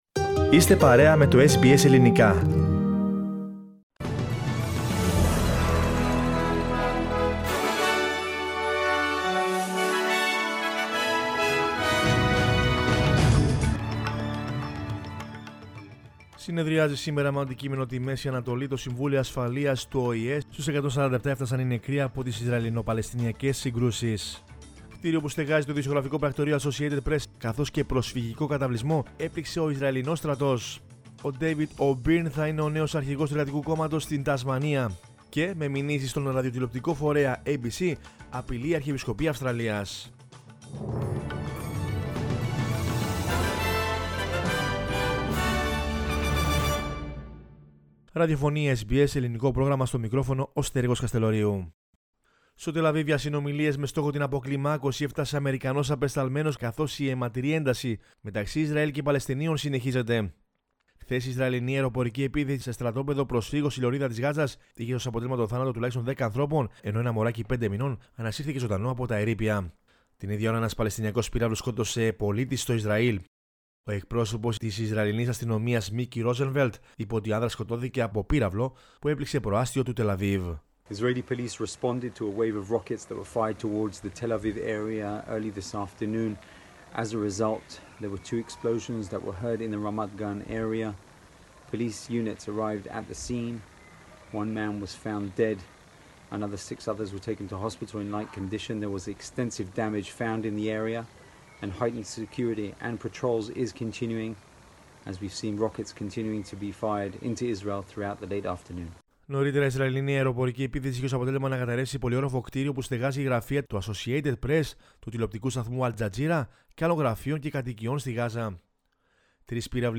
News in Greek from Australia, Greece, Cyprus and the world is the news bulletin of Sunday 16 May 2021.